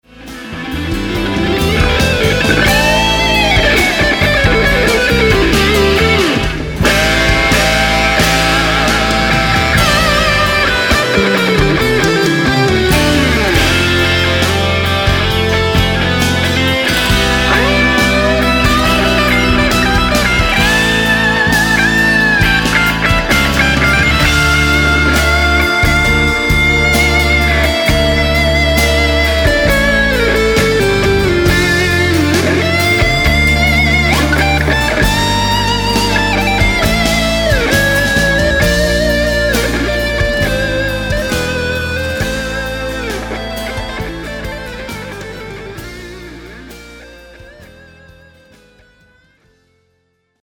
guitars
drums
bass
keyboards
piano, keyboards